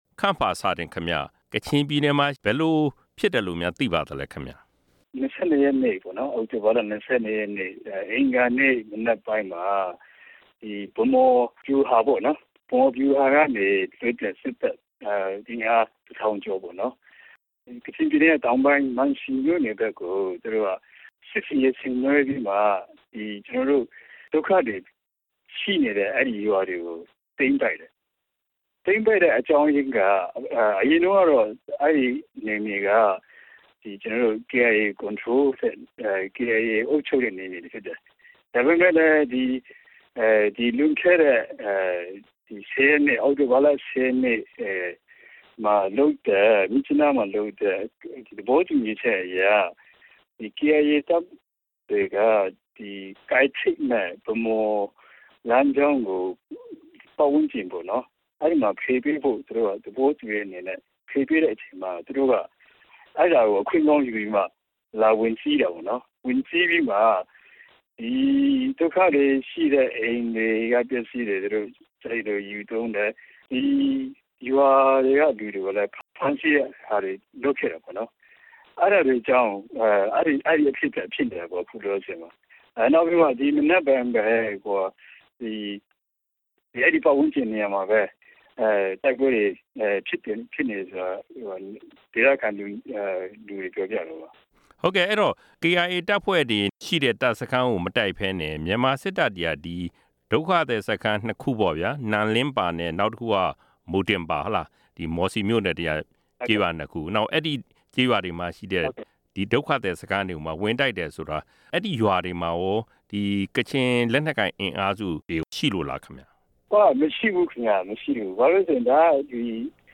မေးမြန်းခန်း